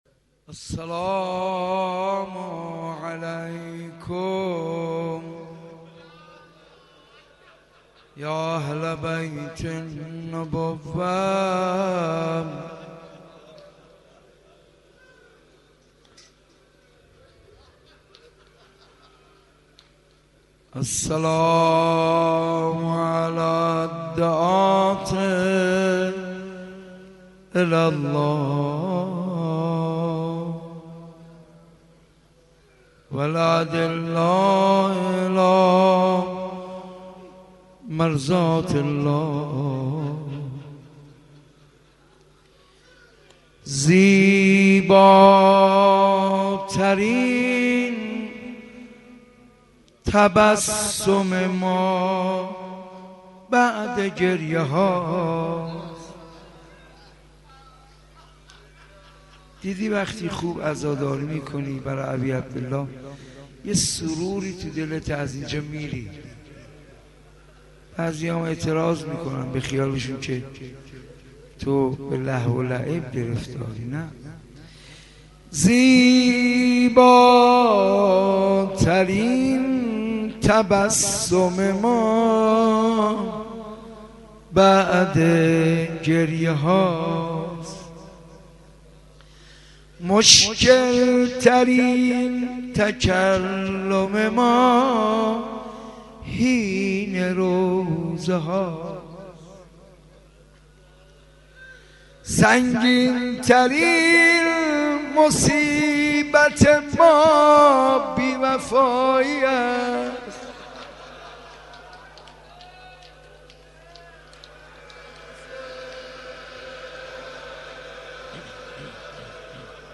مناسبت : شب هشتم محرم
قالب : مجلس کامل